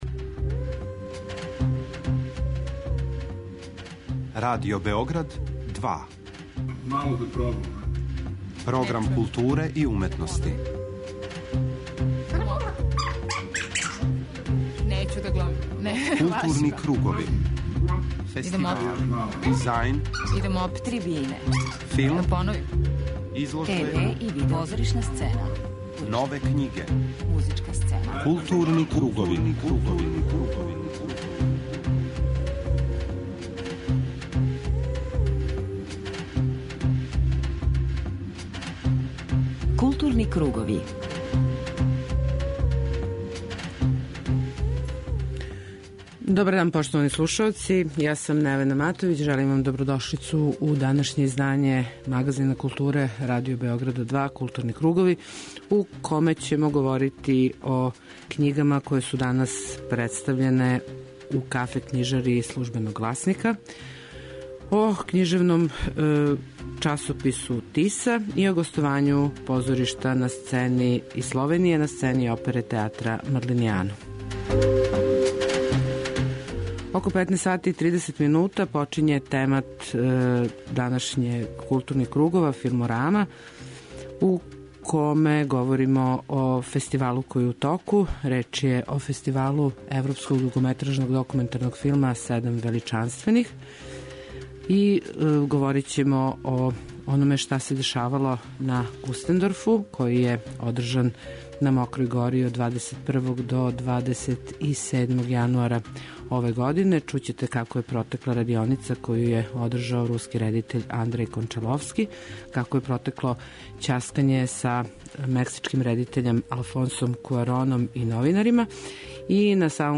У наставку темата слушаћете разговоре и звучне белешке са 8. Међународног филмског и музичког фестивала Кустендорф, који је одржан на Мокрој гори од 21. до 27. јануара.
преузми : 40.20 MB Културни кругови Autor: Група аутора Централна културно-уметничка емисија Радио Београда 2.